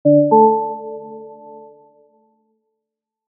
Download Wrong Answer sound effect for free.
Wrong Answer